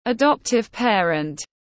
Bố mẹ nuôi tiếng anh gọi là adoptive parent, phiên âm tiếng anh đọc là /əˈdɒp.tɪv ˈpeə.rənt/.
Adoptive parent /əˈdɒp.tɪv ˈpeə.rənt/